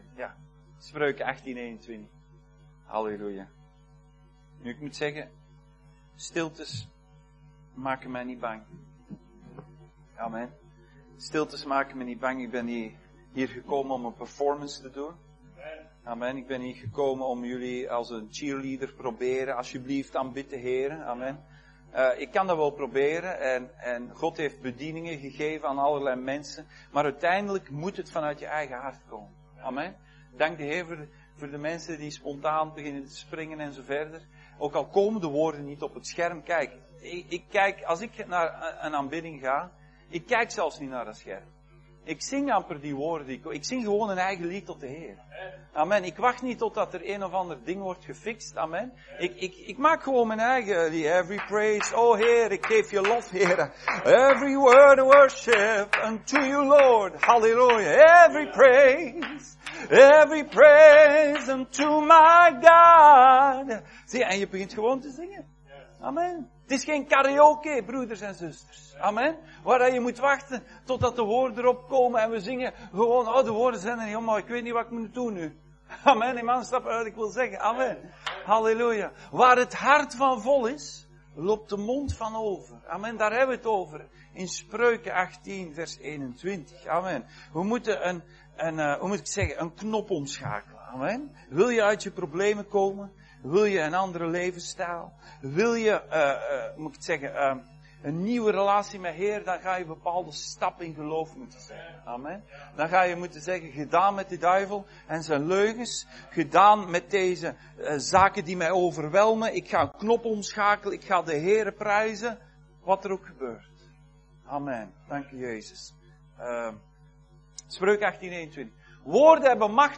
2024 Dienstsoort: Zondag Dienst « De Tabernakel